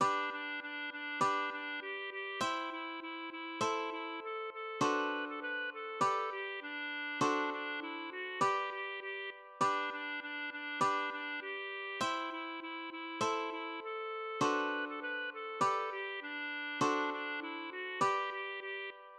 / Komponist: hügelzwergische Weise (traditional)
Das Gedicht wird im Schlund mittlerweile gerne auf die Melodie einer alten hügelzwergischen Weise gesungen, für die immer ein "Hilf, Draconius Riesentodt" eingefügt wird: